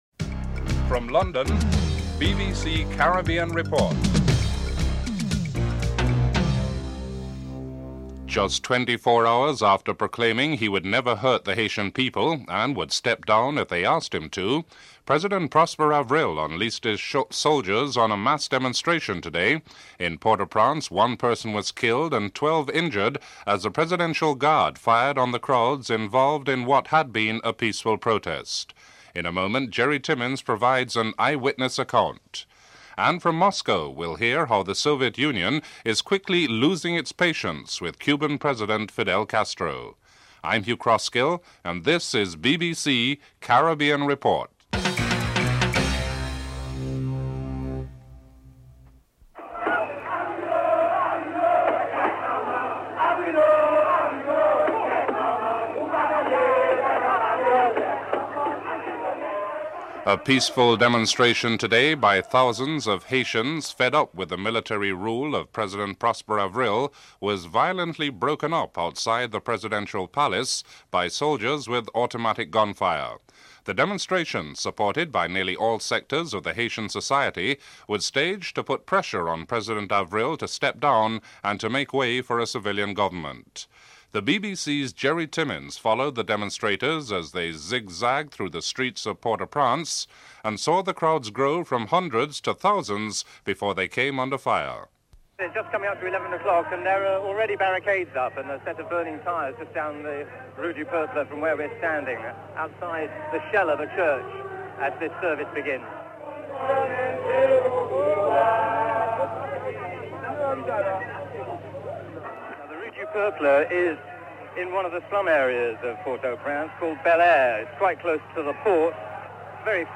dc.description.tableofcontents1. Headlines (00:00-01:48)en_US
dc.typeRecording, oralen_US